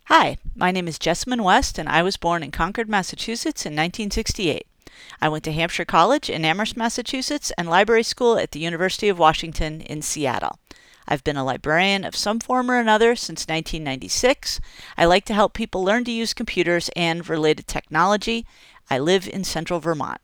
Voice sample for Wikipedia Voice Intro project